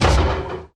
sounds / mob / irongolem / hit2.mp3